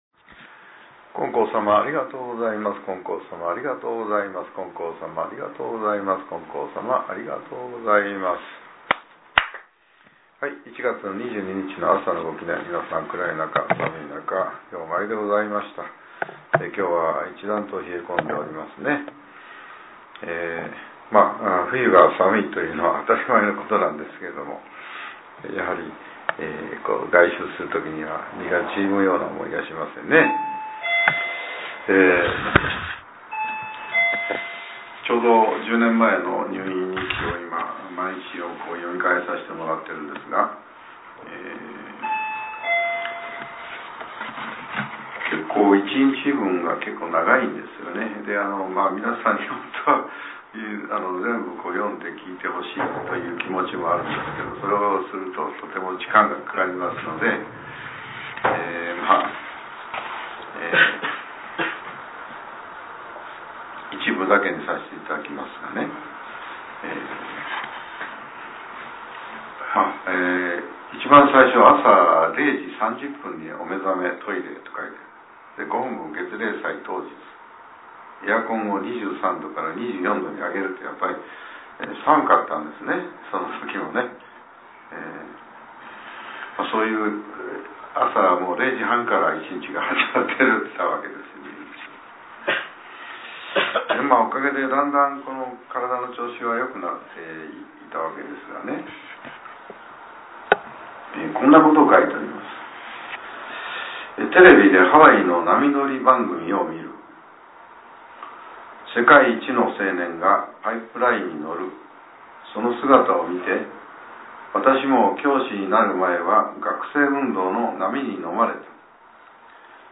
令和８年１月２２日（朝）のお話が、音声ブログとして更新させれています。